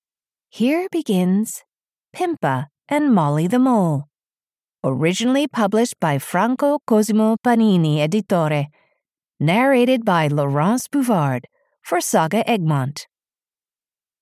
Pimpa - Pimpa and Molly the Mole (EN) audiokniha
Ukázka z knihy